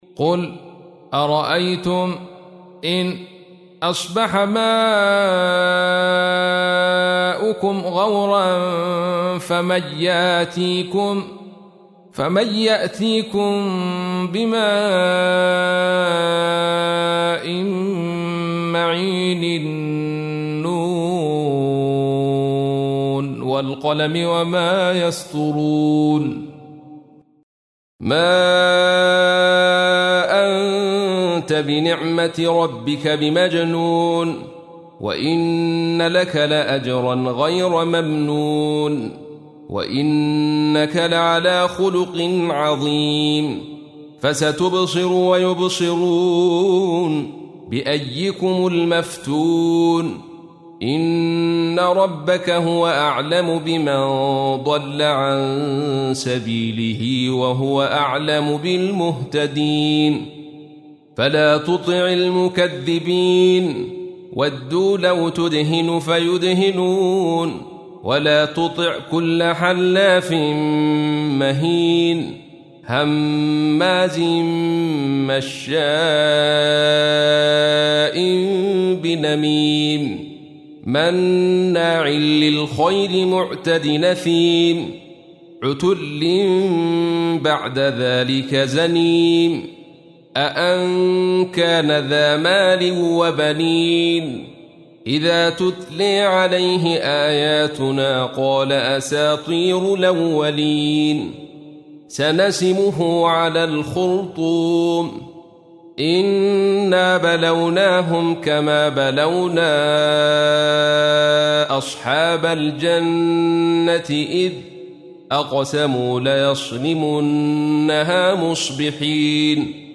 تحميل : 68. سورة القلم / القارئ عبد الرشيد صوفي / القرآن الكريم / موقع يا حسين